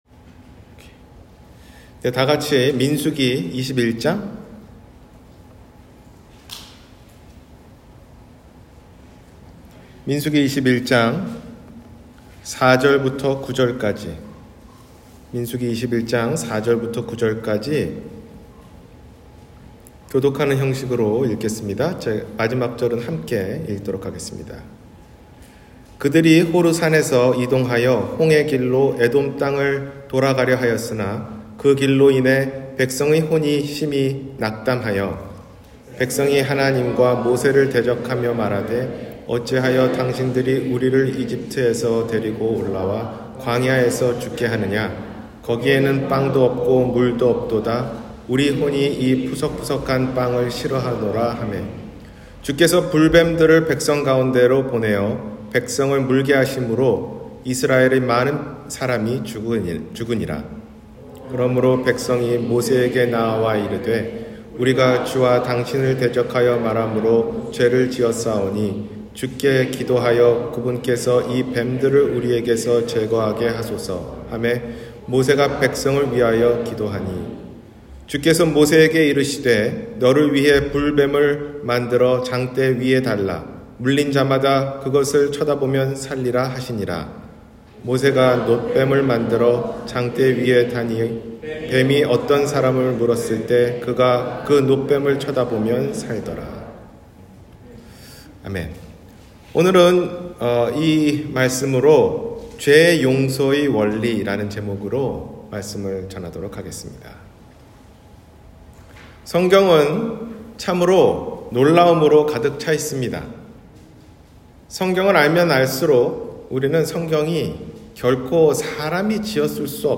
죄 용서의 원리 – 주일설교